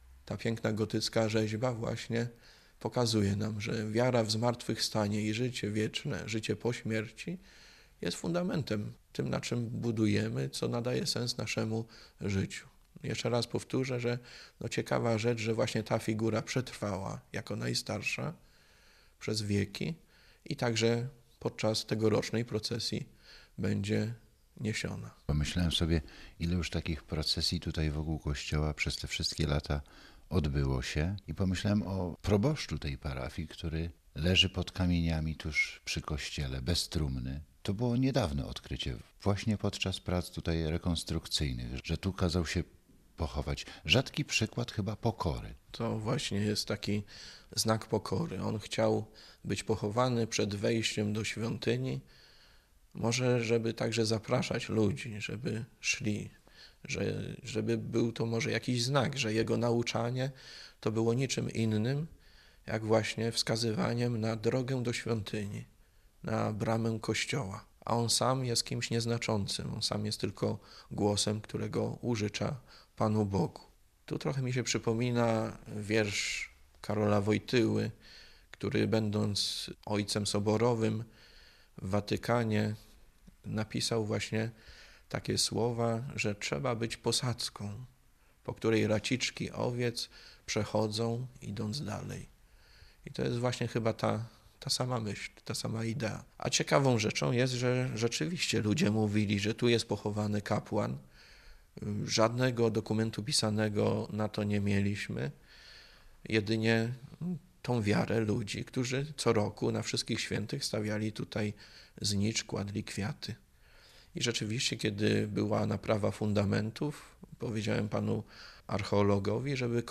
Zapowiedź programu Radia PiK